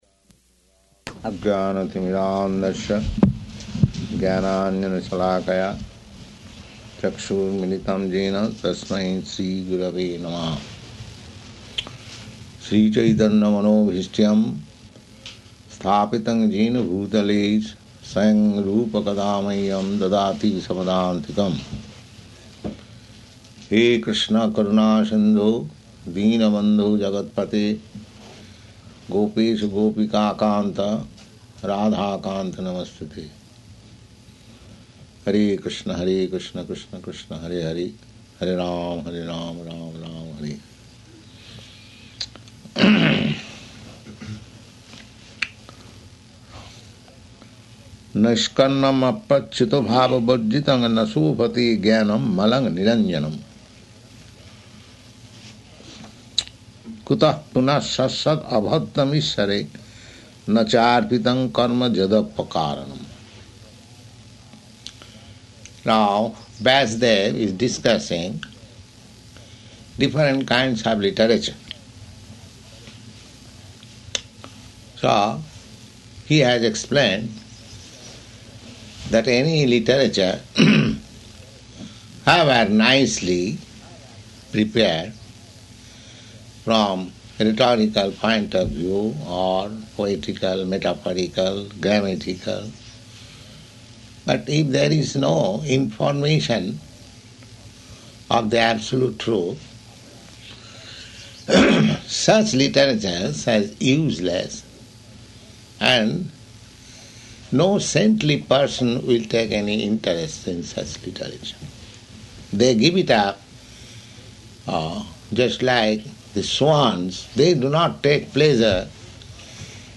Type: Srimad-Bhagavatam
Location: New Vrindavan